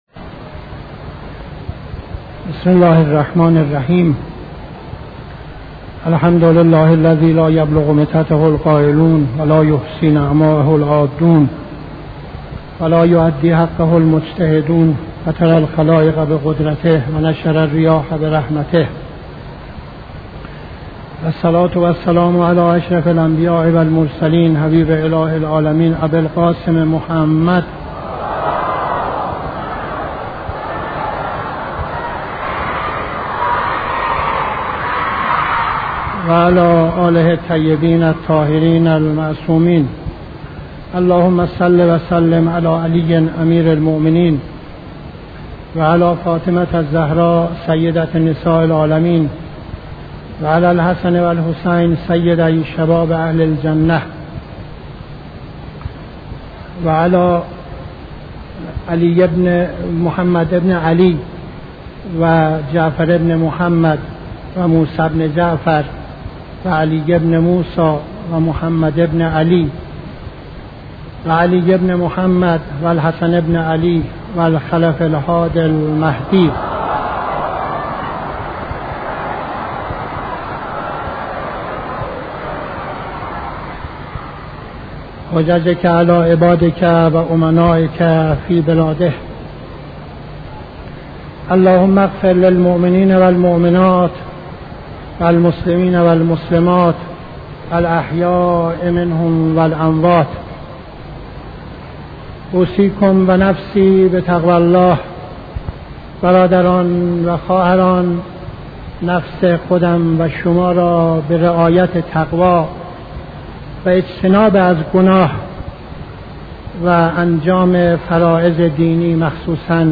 خطبه دوم نماز جمعه 15-08-71